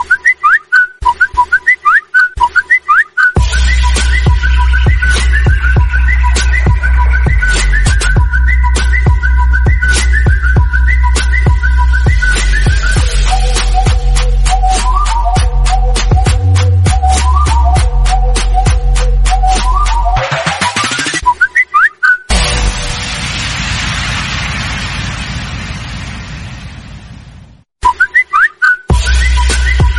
Samsung Phone Sound Effect Free Download
Samsung Phone